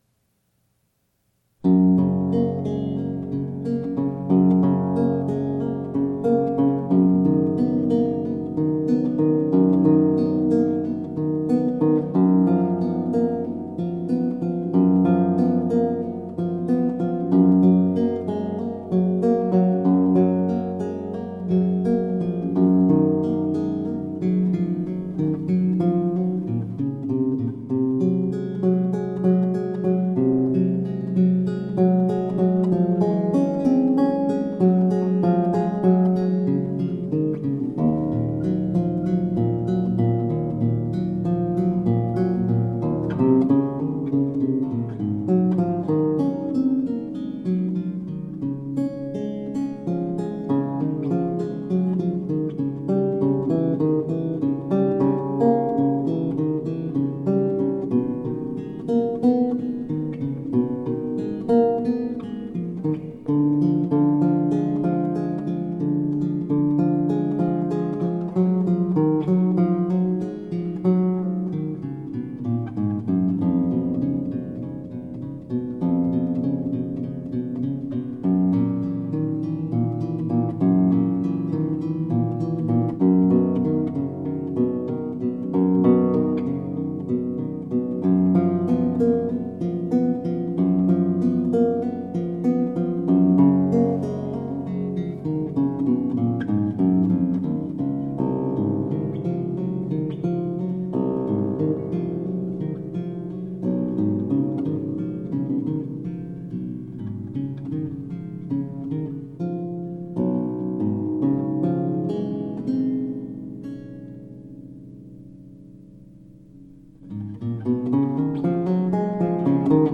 Lilting renaissance & baroque vocal interpretations .
solo theorbo